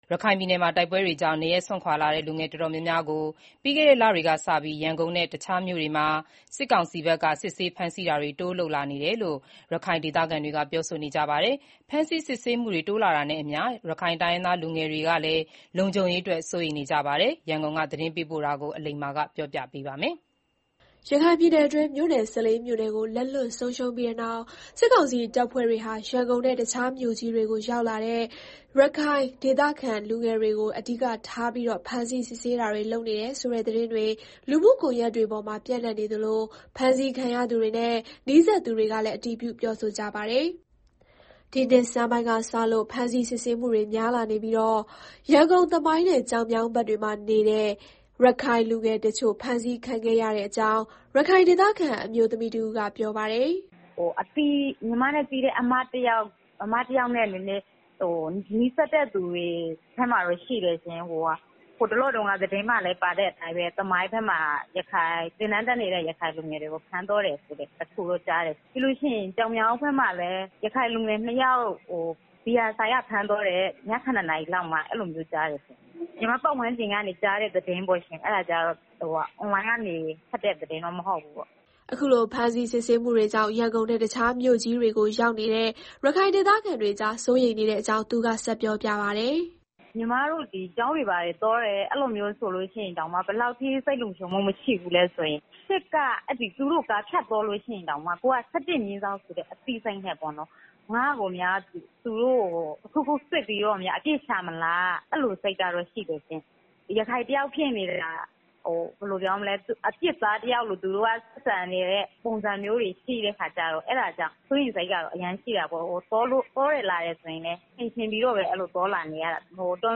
ရခိုင်ပြည်နယ်မှာ တိုက်ပွဲတွေကြောင့်နေရပ်စွန့်ခွာလာကြတဲ့ လူငယ်တော်တော်များကို ပြီးခဲ့တဲ့ လတွေ ကစပြီး ရန်ကုန်နဲ့ တခြားမြို့တွေမှာ စစ်ကောင်စီဘက်က တိုးပြီး စစ်ဆေးဖမ်းဆီးလာနေတယ်လို့ ရခိုင်ဒေသခံတွေက ပြောဆိုကြပါတယ်။ ဖမ်းဆီးစစ်ဆေးမှုတွေ တိုးလာတာနဲ့အမျှ ရခိုင်တိုင်းရင်းသား လူငယ်တွေ လုံခြုံရေးအတွက် စိုးရိမ်နေကြတာပါ။ ရန်ကုန်က သတင်းပေးပို့ထားပါတယ်။
ရခိုင်ပြည်နယ်အတွင်း မြို့နယ် ၁၄ မြို့နယ်ကို လက်လွတ်ဆုံးရှုံးပြီးတဲ့နောက် စစ်ကောင်စီတပ်ဖွဲ့တွေဟာ ရန်ကုန်နဲ့ တခြားမြို့ကြီးတွေကို ရောက်လာတဲ့ ရခိုင်ဒေသခံ လူငယ်တွေကို အဓိကထားပြီး ဖမ်းဆီးစစ်ဆေးနေတယ် ဆိုတဲ့ သတင်းတွေ လူမှုကွန်ယက်တွေ ပေါ်မှာ ပျံ့နှံ့နေသလို ဖမ်းဆီးခံရသူတွေနဲ့ နီးစပ်သူတွေကလည်း အတည်ပြု ပြောဆိုကြပါတယ်။ ဒီနှစ်ဆန်းပိုင်းက စလို့ ဖမ်းဆီးစစ်ဆေးမှုတွေ များလာနေပြီး ရန်ကုန် သမိုင်းနဲ့ ကျောက်မြောင်းဘက်တွေမှာ‌နေတဲ့ ရခိုင်လူငယ်တချို့ ဖမ်းဆီးခံခဲ့ရတဲ့အကြောင်း ရခိုင်ဒေသခံ အမျိုးသမီးတဦးကပြောပါတယ်။